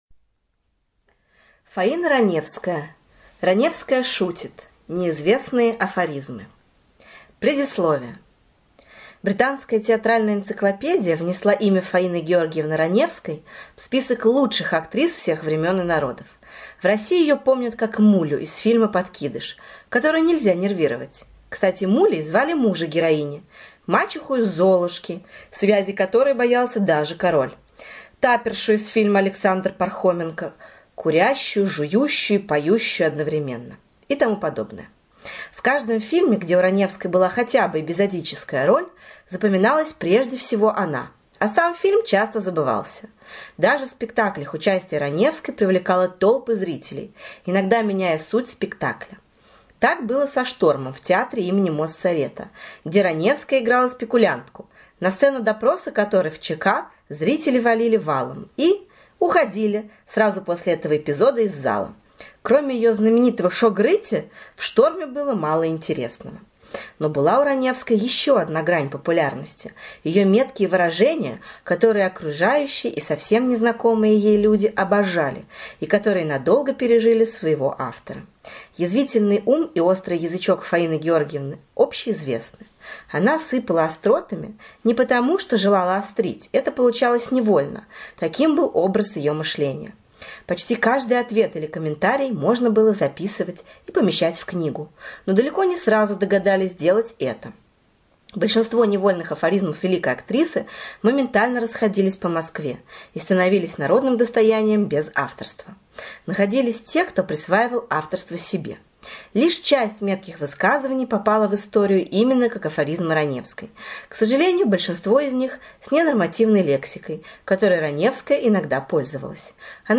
Читает отвратительно, чамкает, чмокает, неверно ставит ударения.